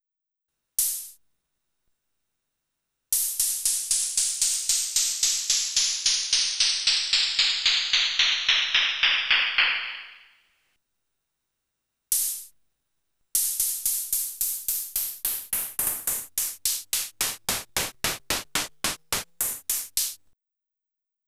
Это же как крут Emu E4 с его десятью октавами транспонирования получается... вот пример записал: сначала с4 семпл исходный - потом две октавы вниз, потом с4 исходный и две октавы вверх... поверху звук резко меняется... Вложения S-10 Example OHH.wav S-10 Example OHH.wav 3,6 MB · Просмотры: 85